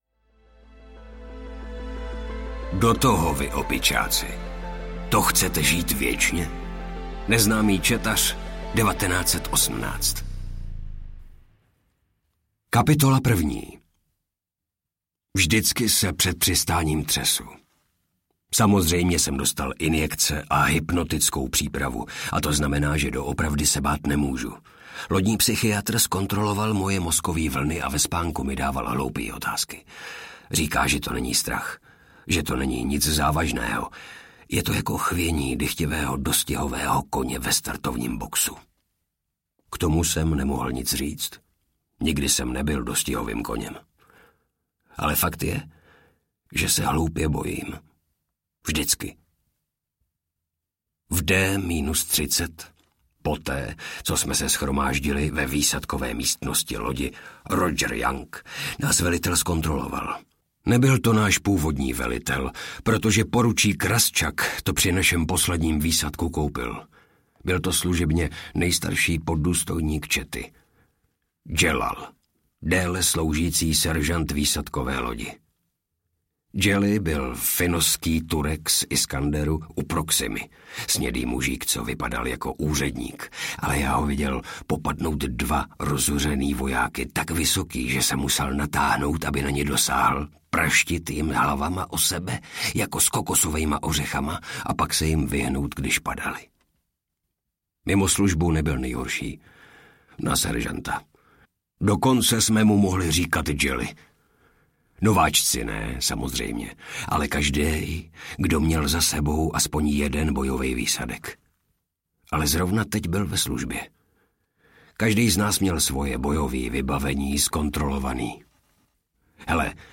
Hvězdná pěchota audiokniha
Ukázka z knihy